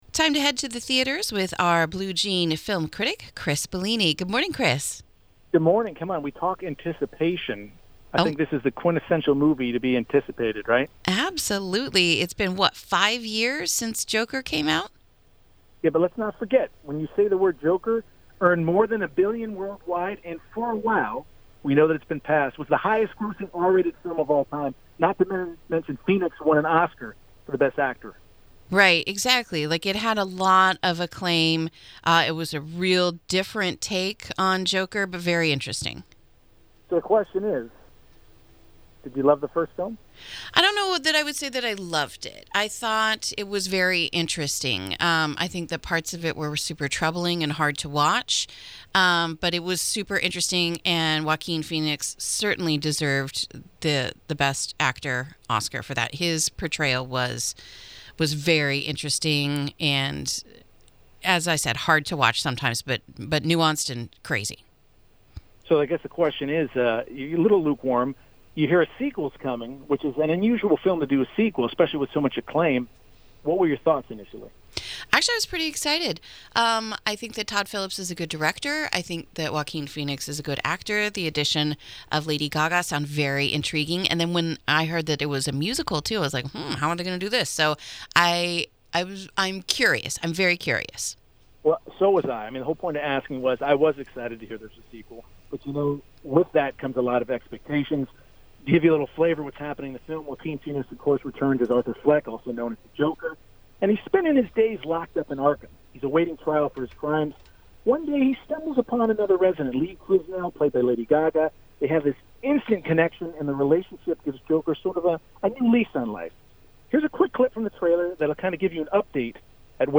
KSRO’s Blue Jean Movie Review of “Joker: Folie à Deux”